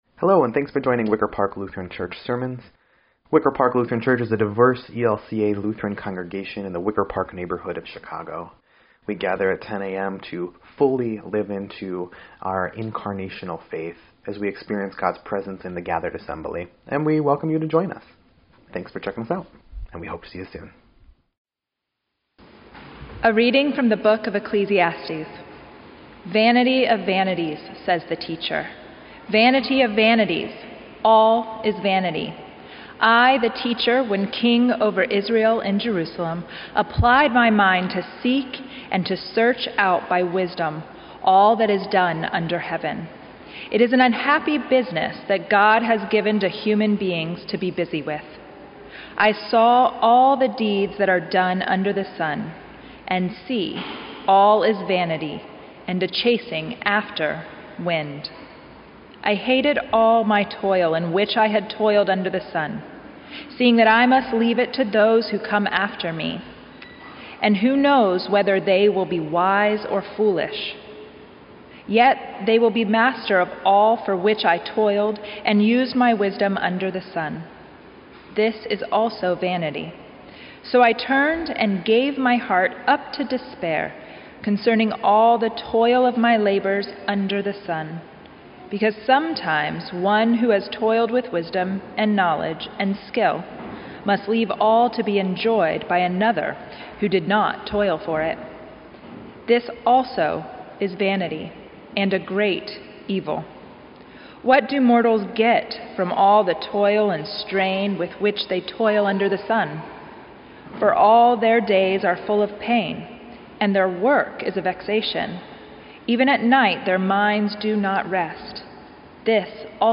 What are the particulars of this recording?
Eighth Sunday After Pentecost